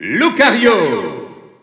Announcer pronouncing Lucario in French PAL.
Category:Lucario (SSBU) Category:Announcer calls (SSBU) You cannot overwrite this file.
Lucario_French_Announcer_SSBU.wav